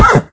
sounds / mob / horse / hit2.ogg
hit2.ogg